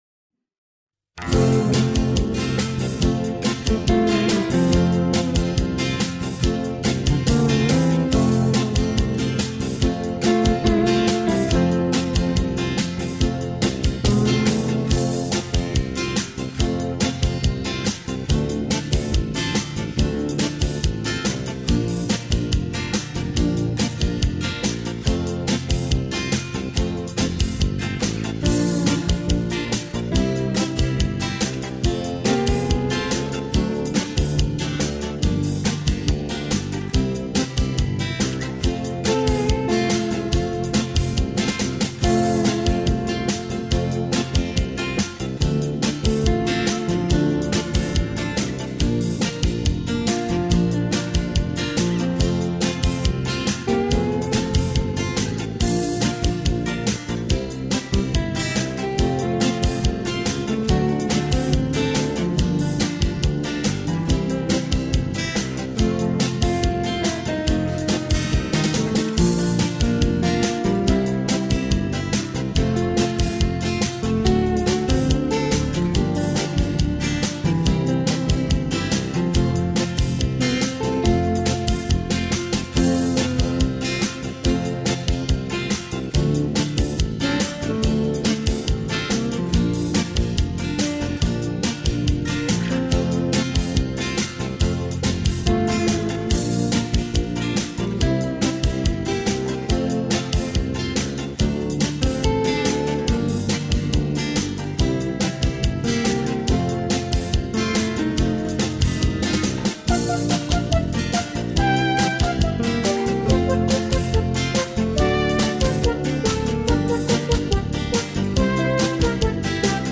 минусовка версия 51782